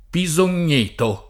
[ pi @ on’n’ % to ]